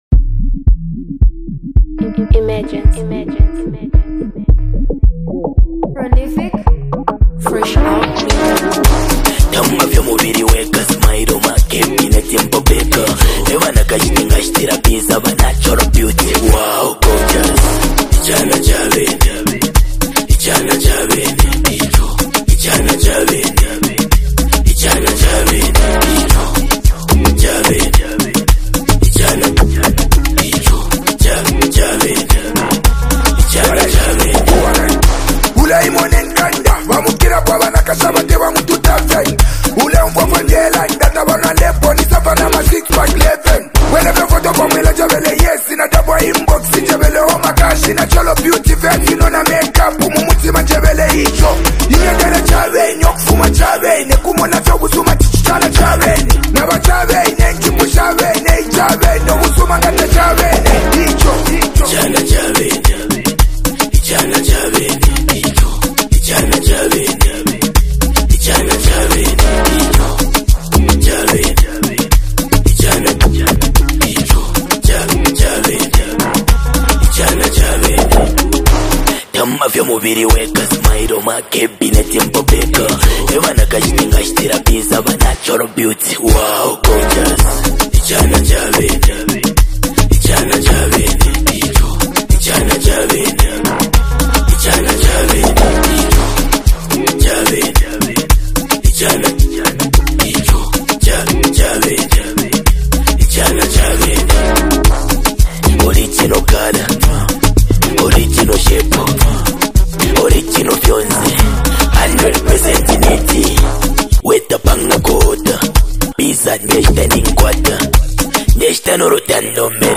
combines infectious beats with compelling lyrics